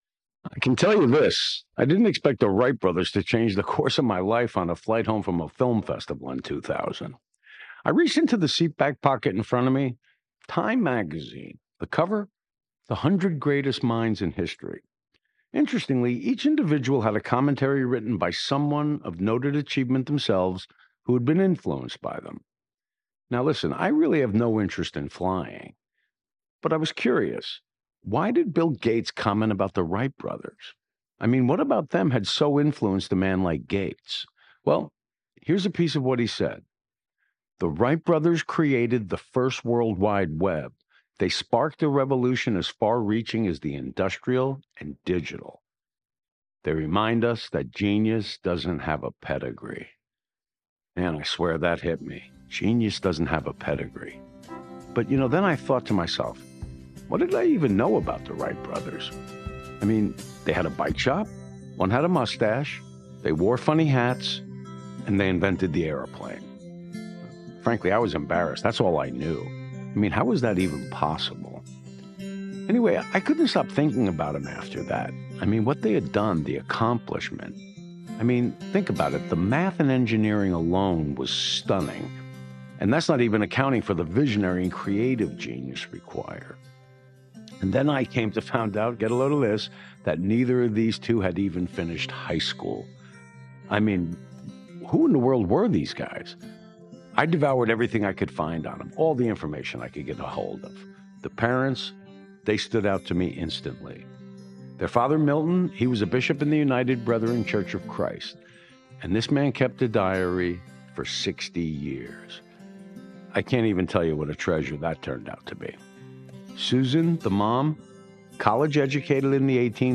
W&O intro and sonic boom.mp3